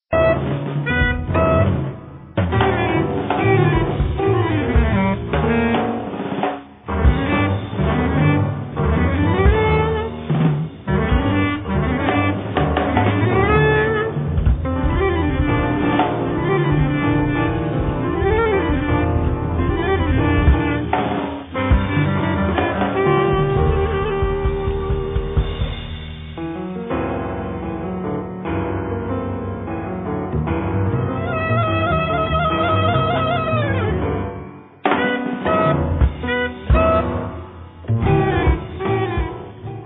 alto sax, clarinetto, clarinetto basso
piano, tastiere, laptop
contrabbasso
batteria, vari oggetti
I temi sono spigolosi, pungenti